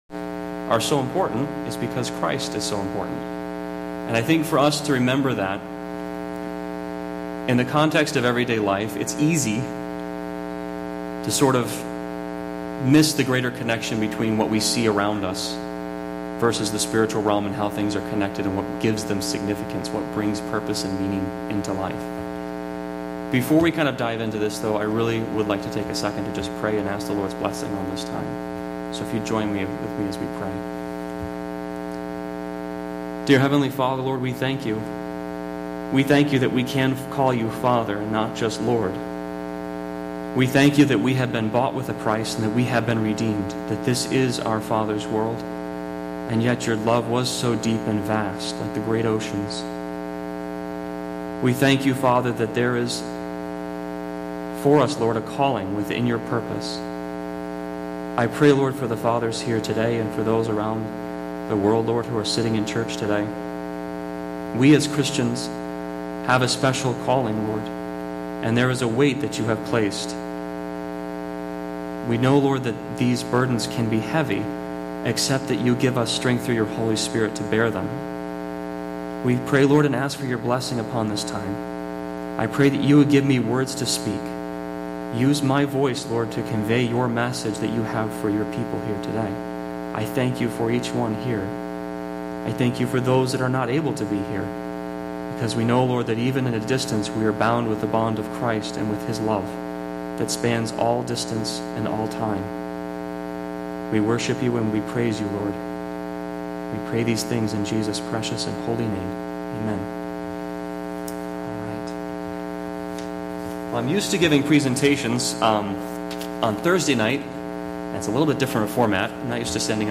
Bible Text: John 3:16 | Preacher